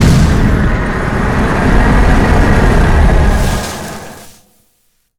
flamethrower_shot_08.wav